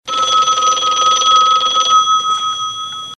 • rotary phone ringing with echo.wav